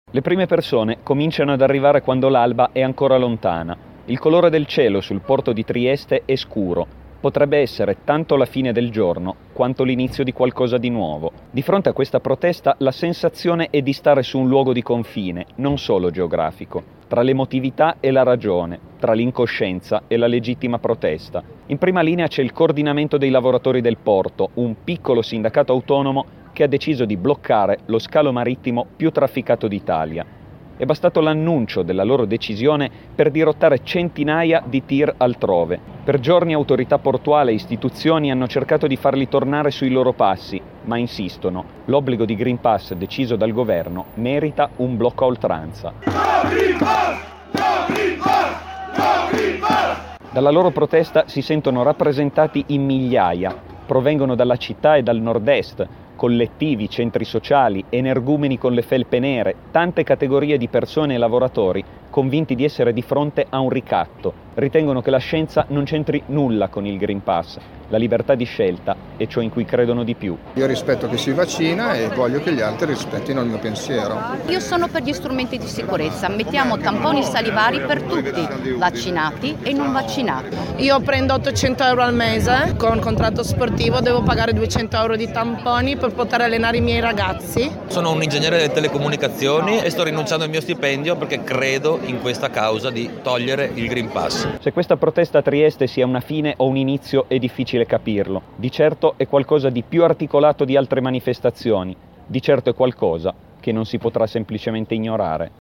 Andiamo a Trieste con il nostro inviato